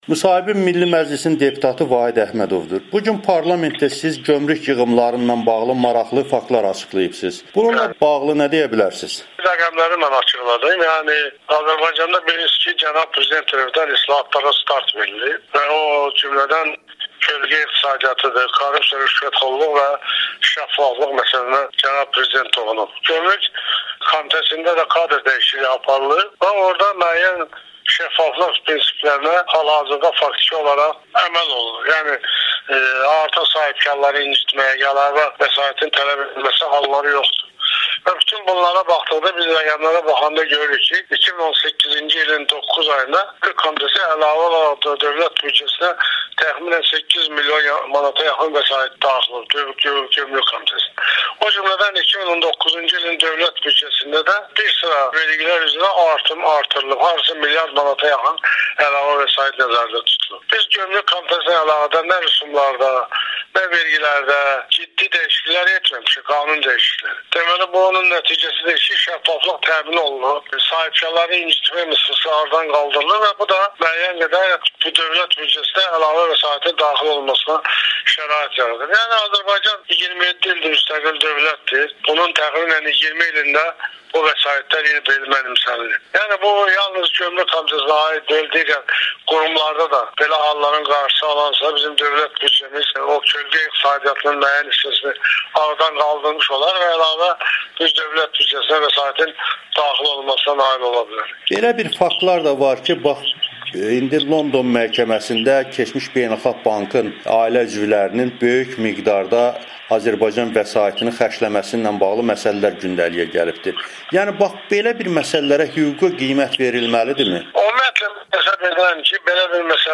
Onun Amerikanın Səsinə müsahibəsini təqdim edirik: